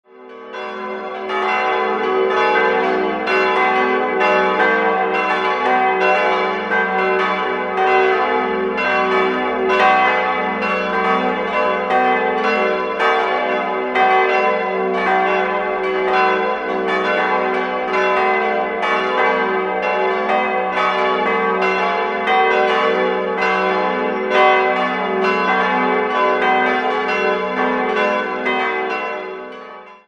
Beschreibung der Glocken
Jahrhundert errichtet und 1903 nach Westen hin verlängert. 4-stimmiges Gloria-TeDeum-Geläute: g'-a'-c''-d'' Eine genaue Glockenbeschreibung folgt unten.